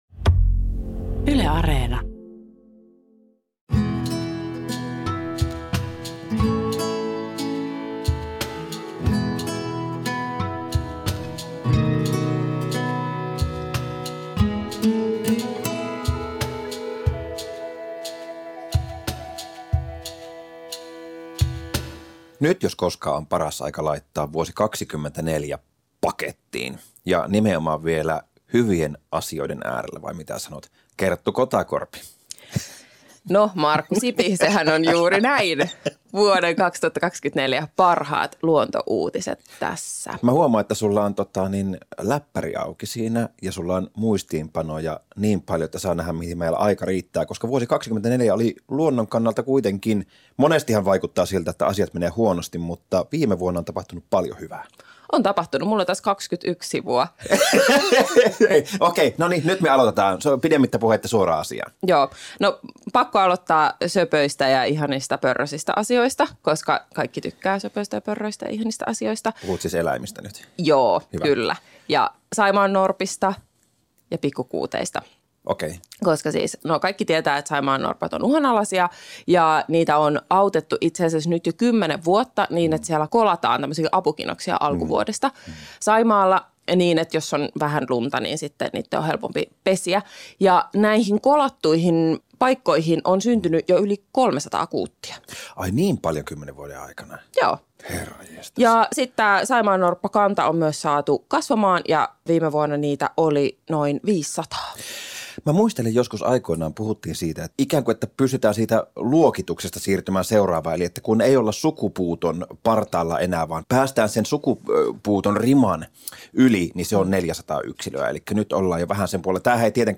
Luonto-Suomi suuntaa Etelä-Karjalaan Hiitolanjoen rannalle.
Lähetykseen voi myös soittaa ja kysellä virtavesien kaloista ja jokien ennallistamisesta.